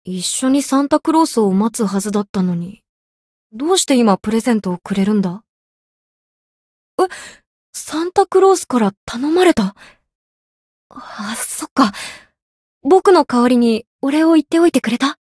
灵魂潮汐-南宫凛-圣诞节（送礼语音）.ogg